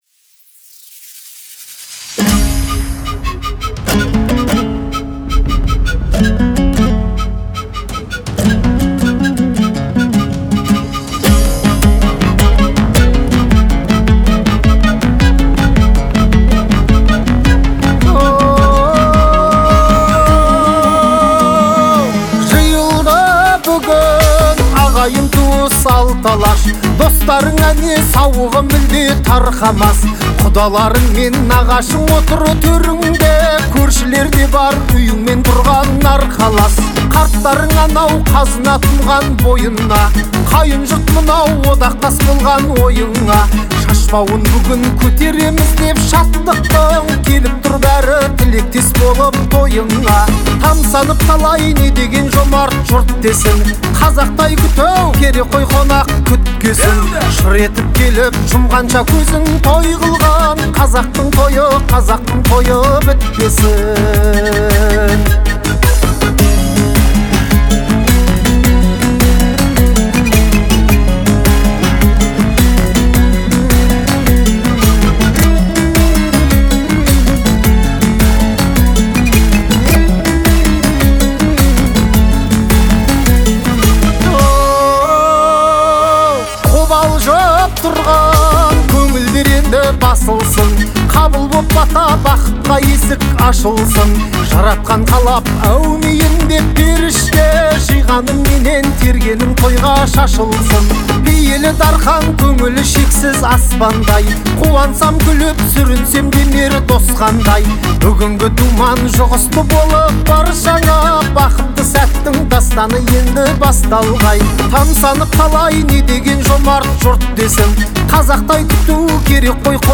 это яркая и жизнеутверждающая песня в жанре народной музыки